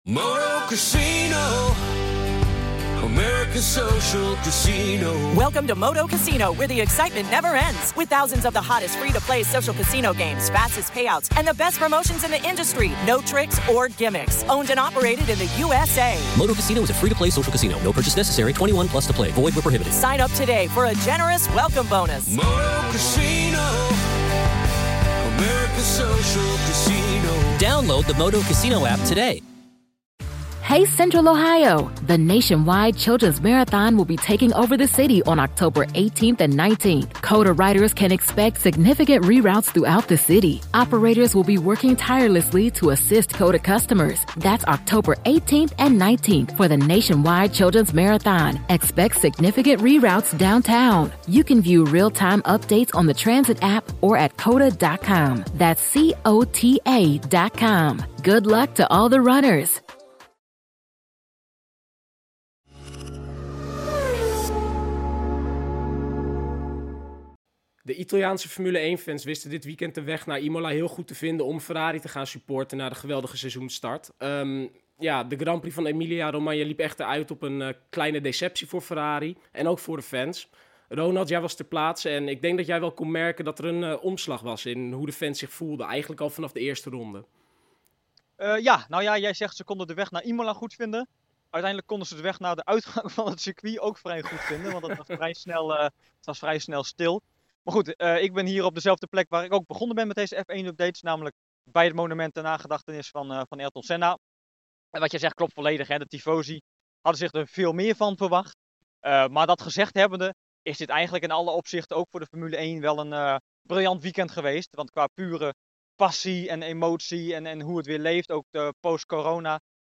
bespreken het allemaal in een nieuwe F1-update vanuit Italië.